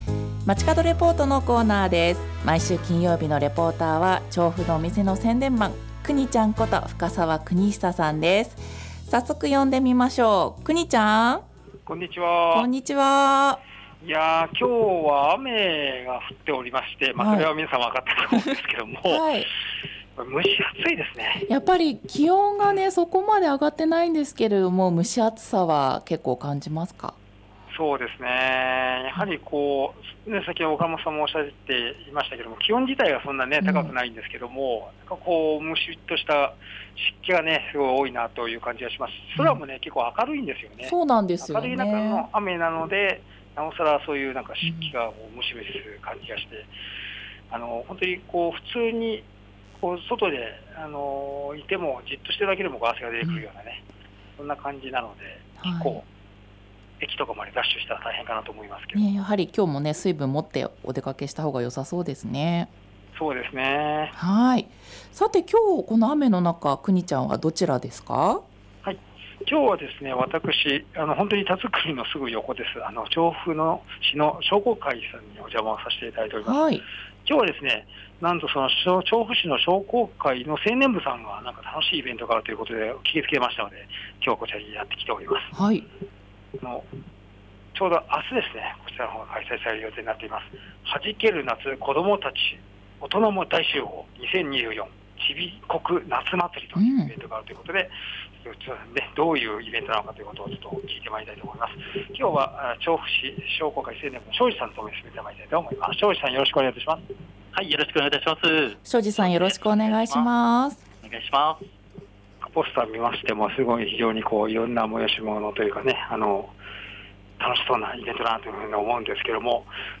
さて本日は調布市商工会さんの会議室に 出没です。